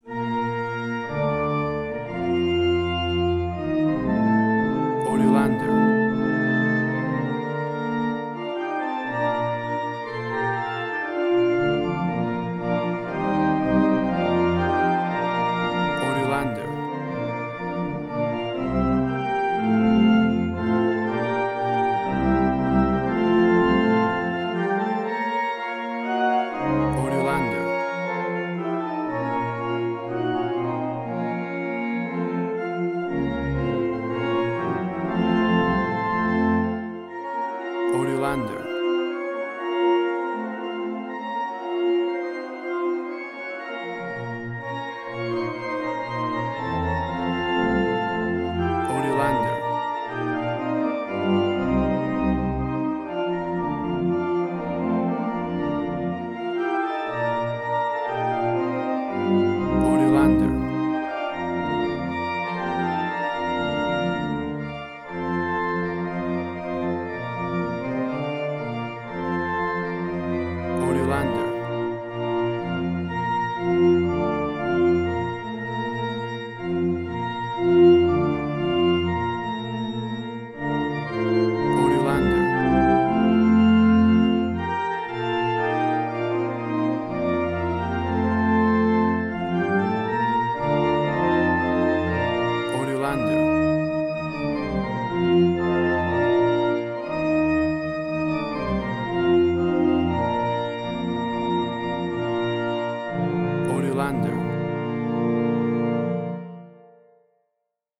A vibrant and heartwarming church organ version
WAV Sample Rate: 16-Bit stereo, 44.1 kHz